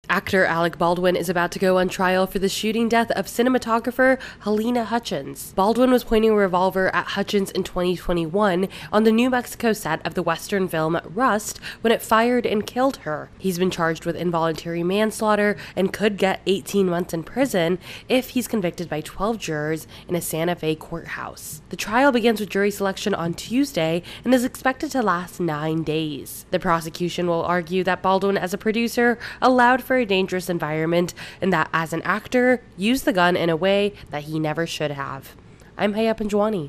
AP correspondent
reports on the upcoming Rust trial.